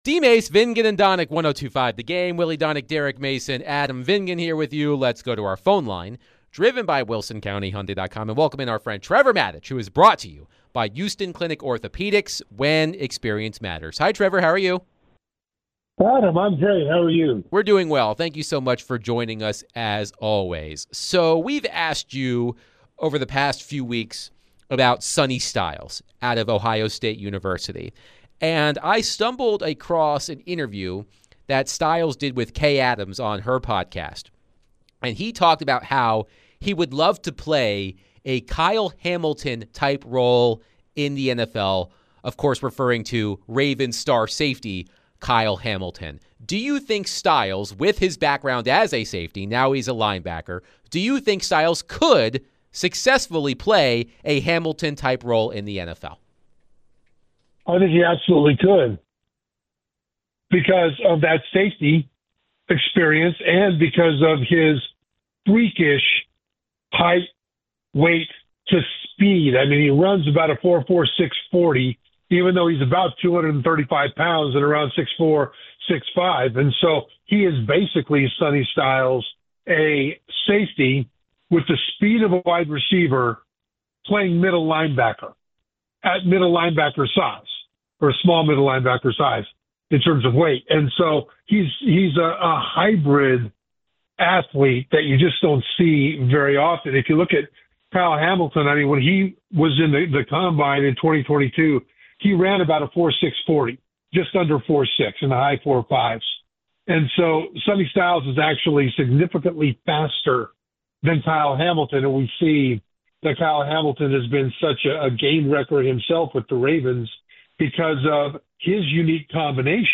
ESPN NFL Analyst Trevor Matich joins DVD to discuss the Titans, the NFL Draft, and more